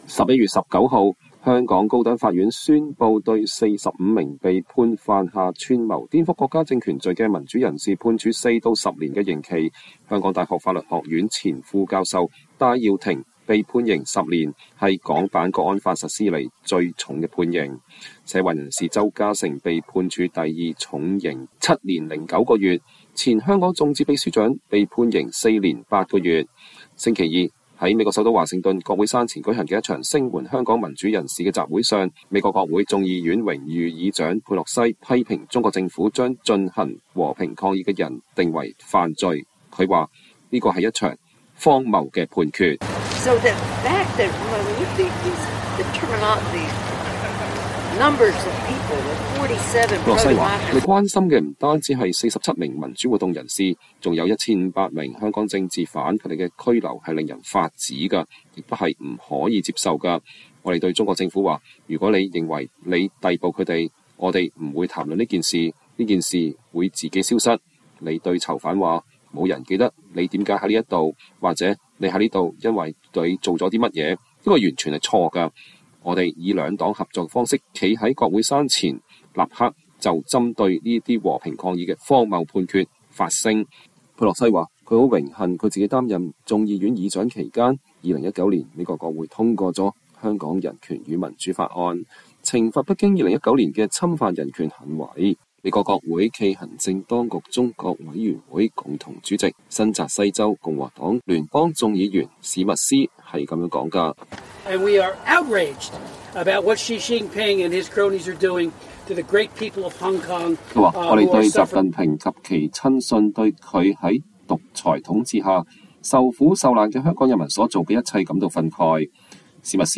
週二，在美國首都華盛頓國會山前舉行的一場聲援香港民主人士的集會上，美國國會眾議院榮譽議長南希·佩洛西(Nancy Pelosi)批評中國政府將進行和平抗議的人定為犯罪，她說，這是一場「荒謬」判決。